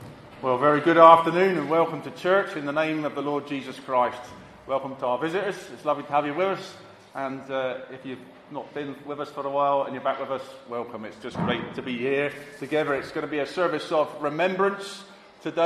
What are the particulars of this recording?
Sunday Service 14th Novemebr 2021